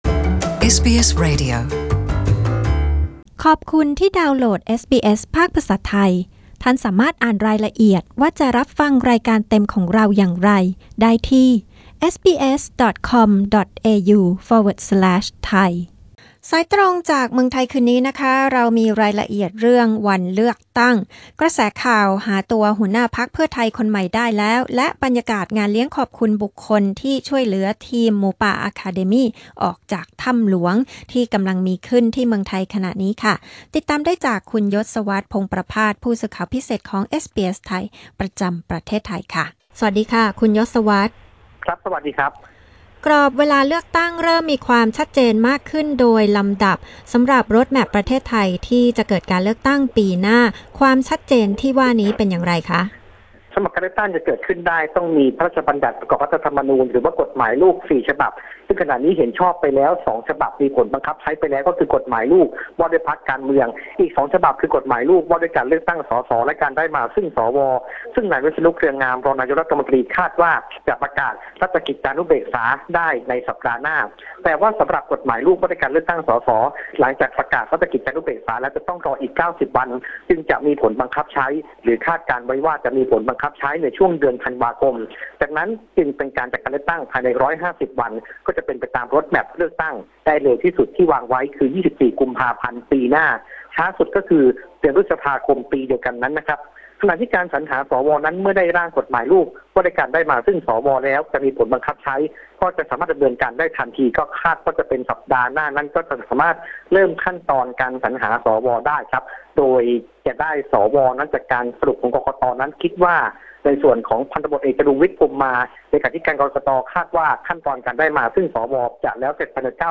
Thai phone-in news 6 SEP 2018